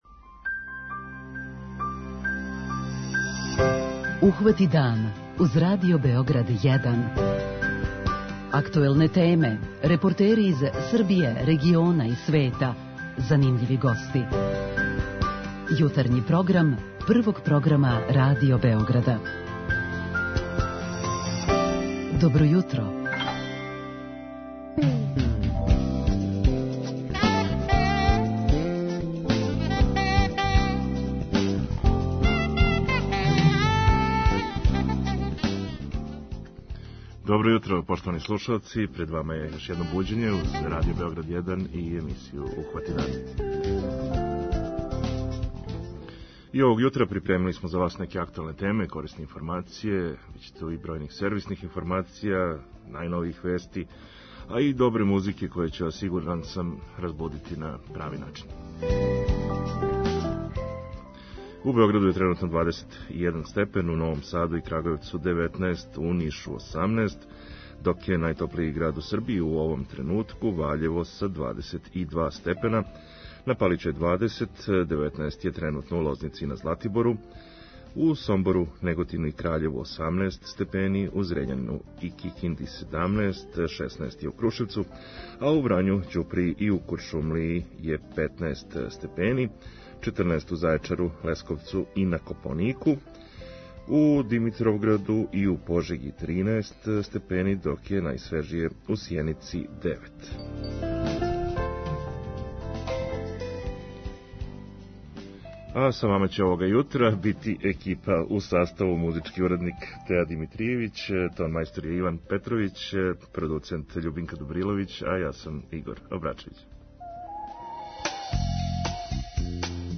Он ће данас бити у улози лутајућег репортера, мада бисмо за ову прилику комотно могли да кажемо и да је ПЛУТАЈУЋИ с обзиром да ће тај део нашег јутарњег програма бити емитован са једног катамарана на Тамишу.